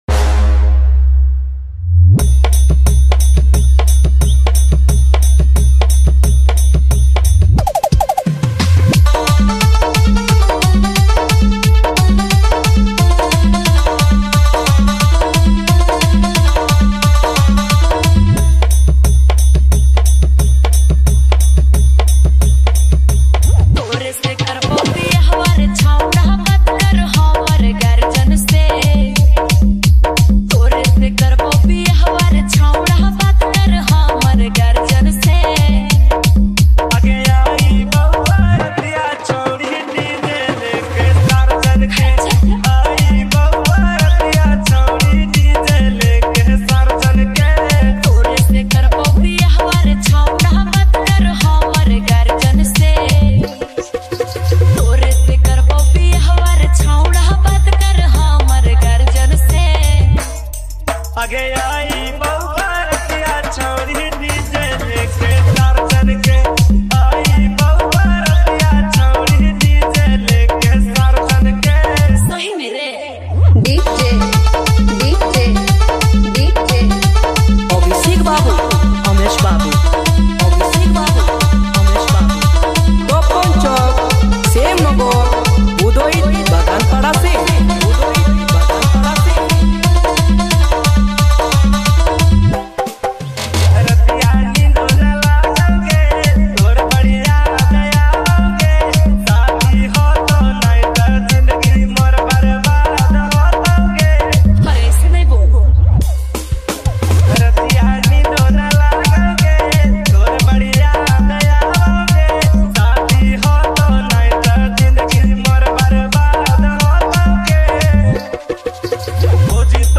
Dj Remixer
New Bhojpuri DJ Songs